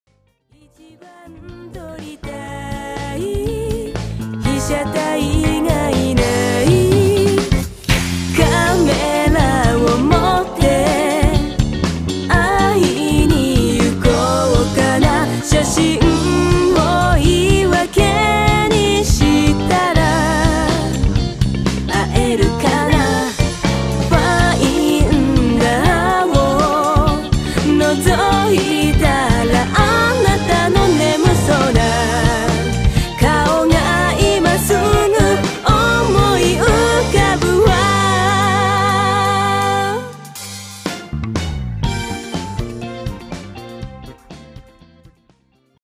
「ちょっと懐かしい昭和な雰囲気」をテーマに、ロックやブルースを詰め込んだミニアルバム。